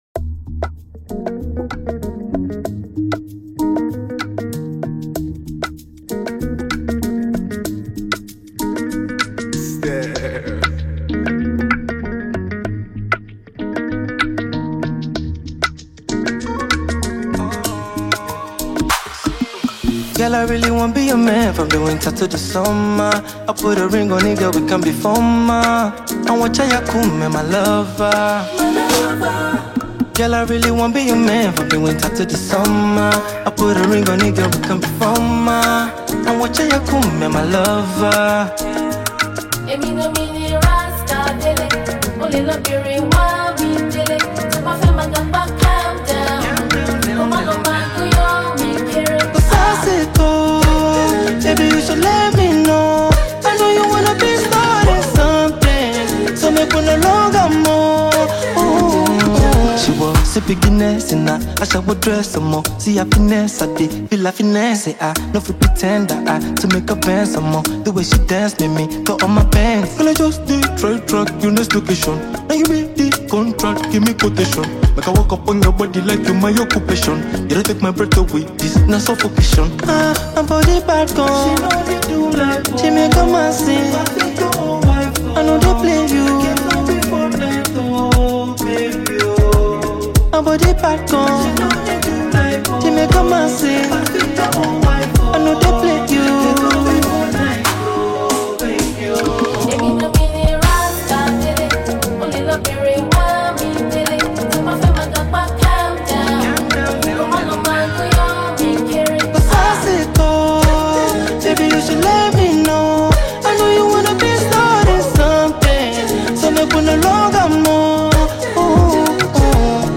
a phenomenally talented Nigerian Afrobeats artist